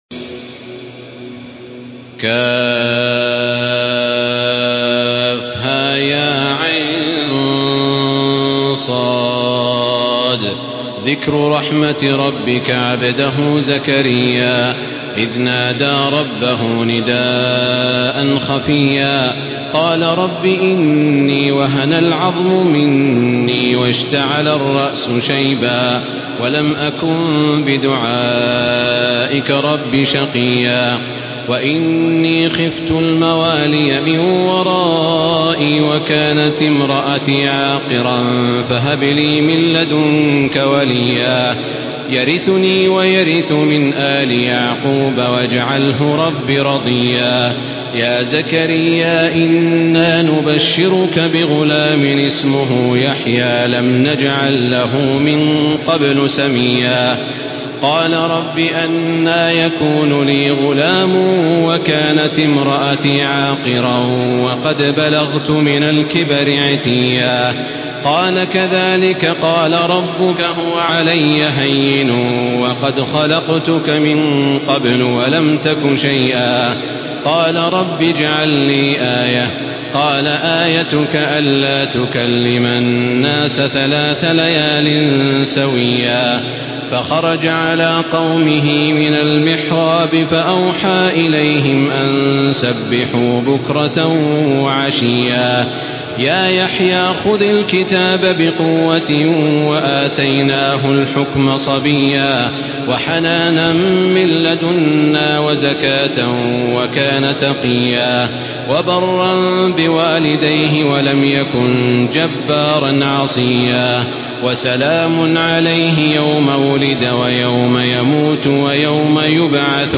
تراويح الحرم المكي 1435
مرتل